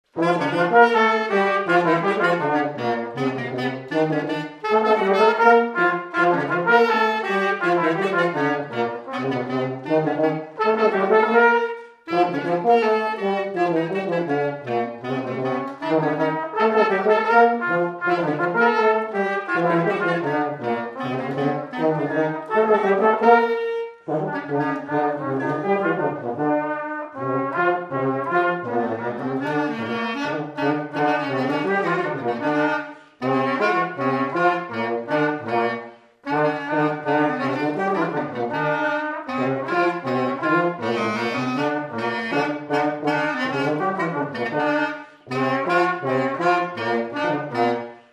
scottish
Chants brefs - A danser
Résumé instrumental
Pièce musicale inédite